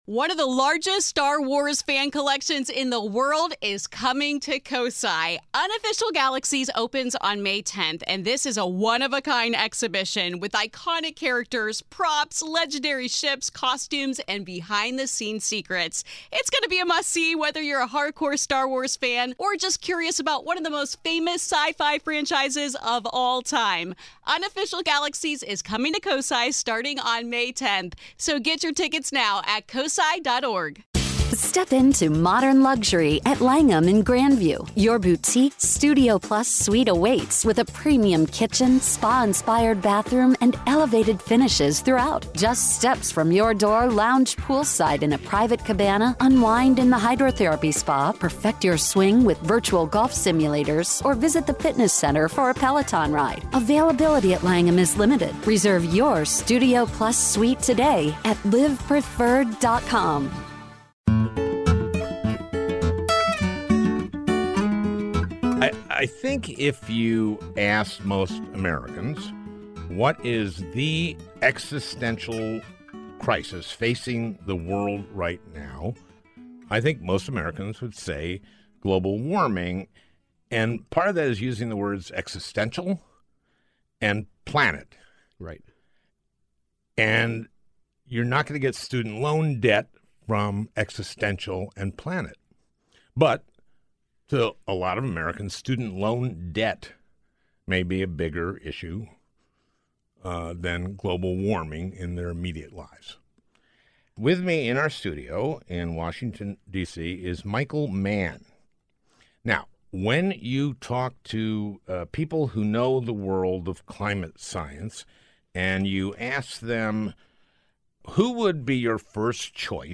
A Conversation with Michael Mann
Al talks to Michael Mann, Nobel Prize-winning climatologist for his work with the Intergovernmental Panel on Climate Change (IPCC).